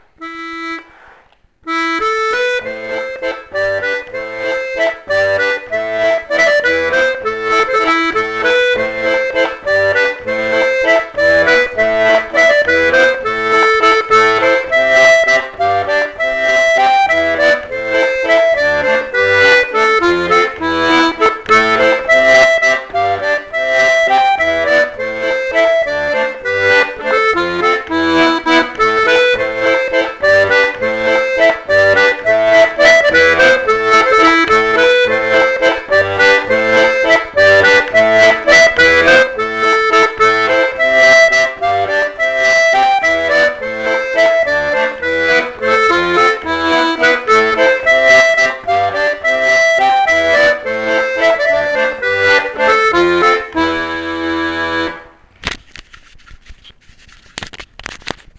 l'atelier d'accordéon diatonique
Valse 5 temps:             la partition  et